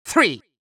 countIn3.wav